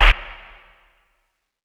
Destroy - R_BSnarePerc.wav